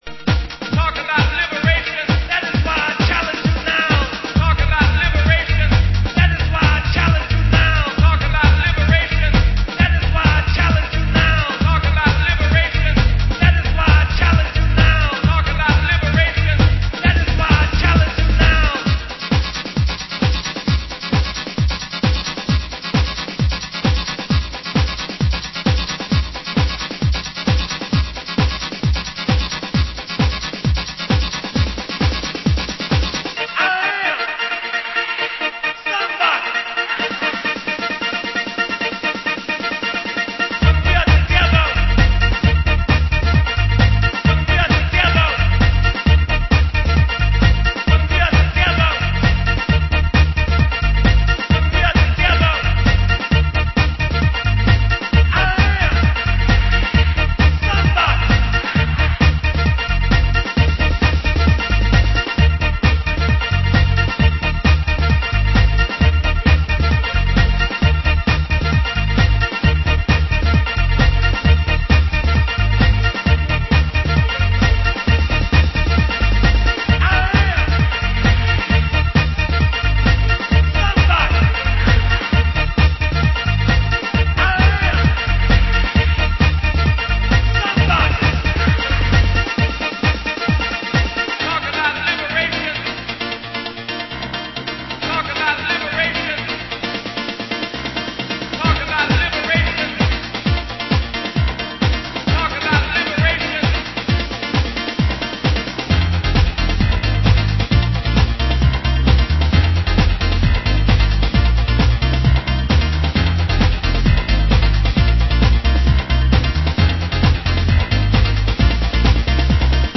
Genre: Euro House